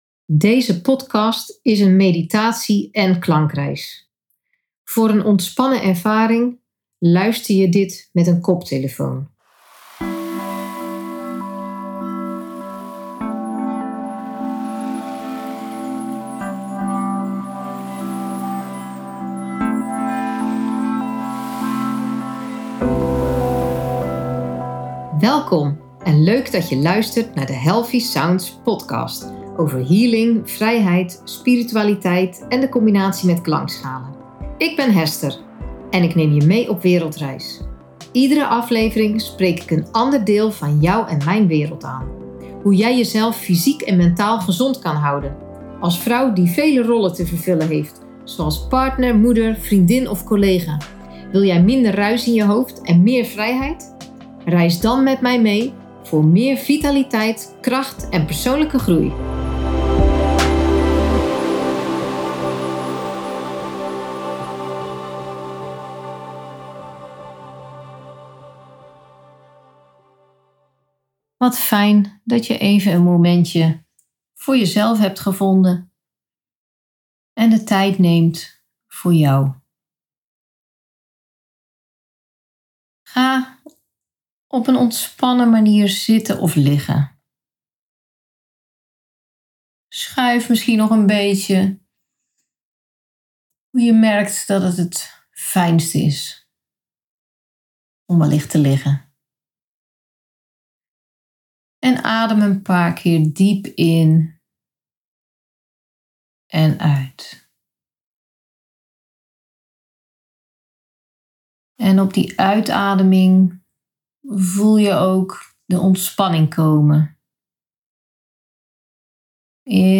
Meditatie en klankreis - 3
Prettig voor iedereen die een moment voor zichzelf wil om tot rust te komen. Zet een koptelefoon op voor de beste ervaring.